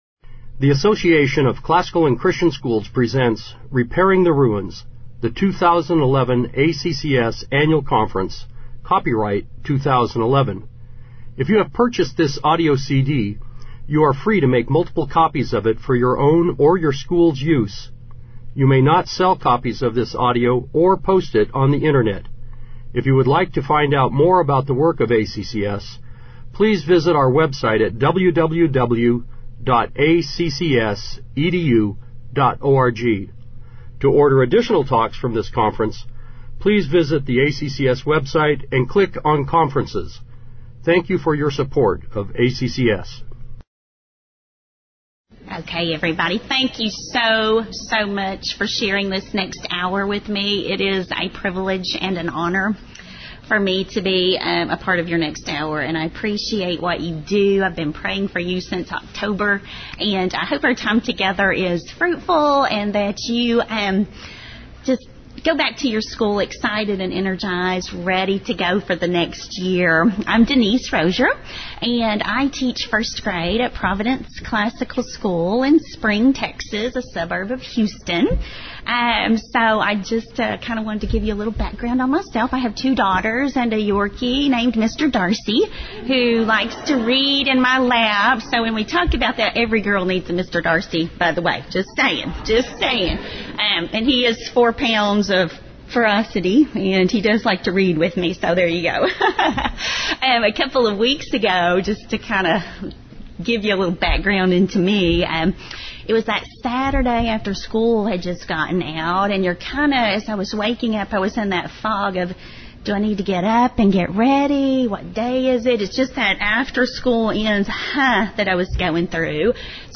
Jan 27, 2019 | Conference Talks, Foundations Talk, General Classroom, K-6, Library, Media_Audio | 0 comments
Speaker Additional Materials The Association of Classical & Christian Schools presents Repairing the Ruins, the ACCS annual conference, copyright ACCS.